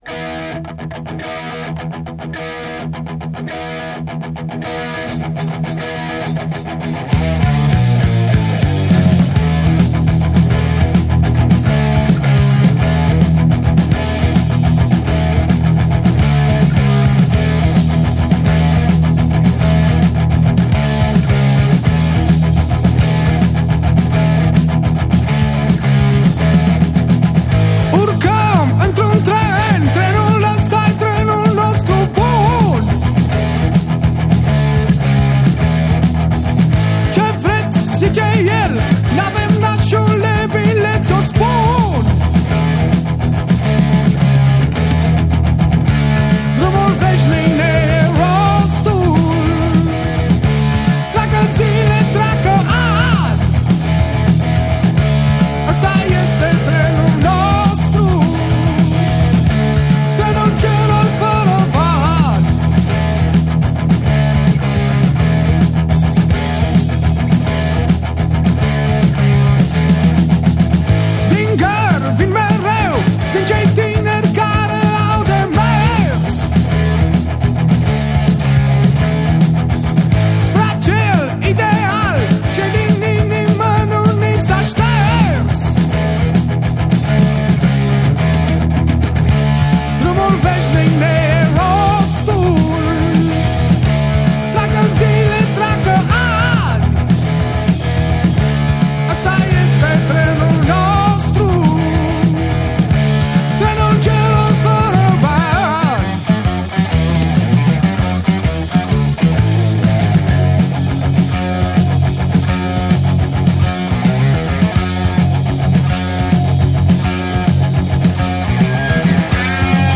un aniversat „de top” al muzicii rock din România